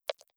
AudioClip_Click-Tap.wav